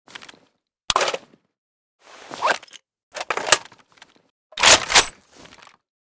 m82_reload_empty.ogg.bak